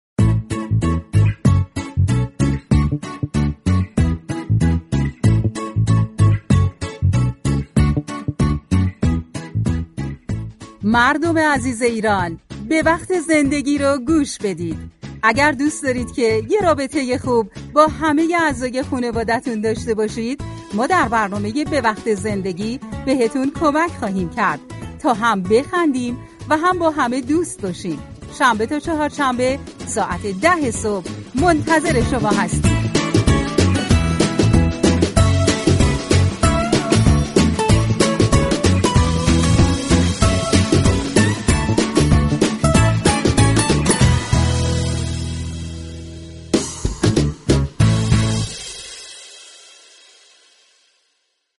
به گزارش روابط عمومی رادیو صبا ، برنامه "به وقت زندگی" كه در قالب مجله رادیویی به بیان مسائل خانه و خانواده با نگاهی طنز می پردازد روز دوشنبه 12 مهر به موضوع تنبلی اختصاص می یابد.